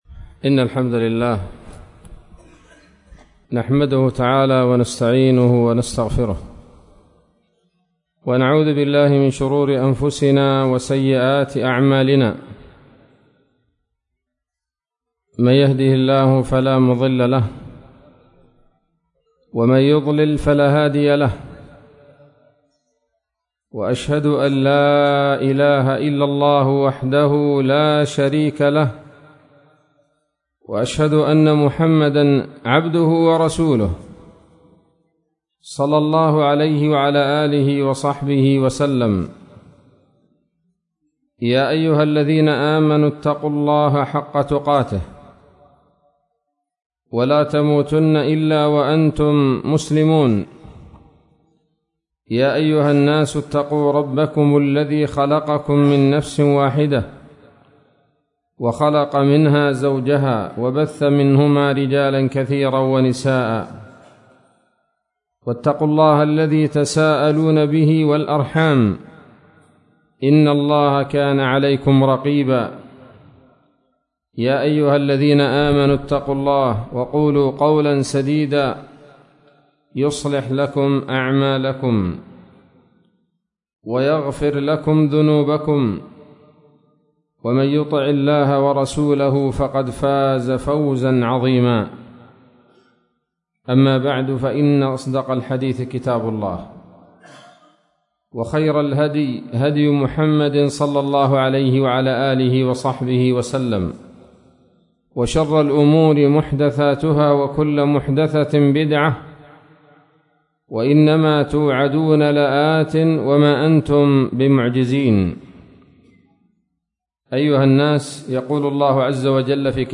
محاضرة قيمة بعنوان: (( ‌الإفادة عن خمس خصال يكون من إدراكها الاستعاذة )) ليلة السبت 2 شعبان 1443هـ، بمسجد الفاروق - البريقة - عدن